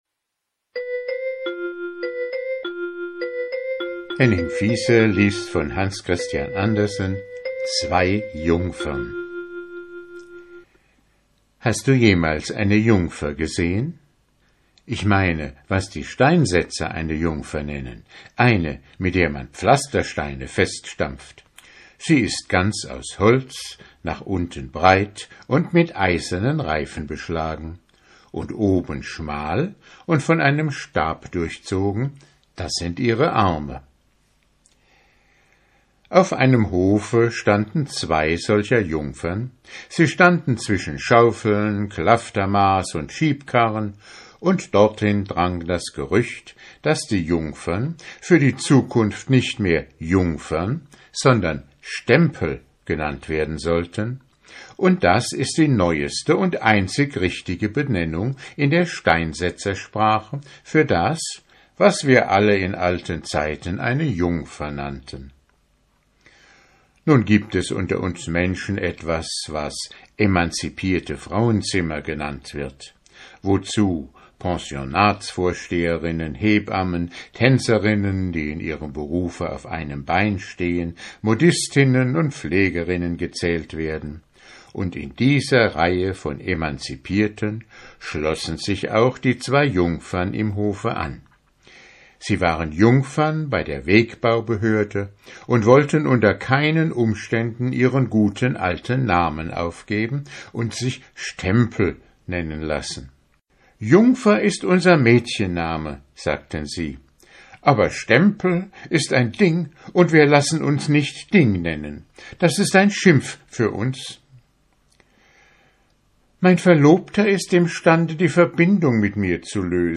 Vorlesezeit 6 min ✓ Alle Grimm und Andersen Märchen in Originalfassung ✓ Online Märchenbuch mit Illustrationen ✓ Nach Lesedauer sortiert ✓ Mp3-Hörbücher ✓ Ohne Werbung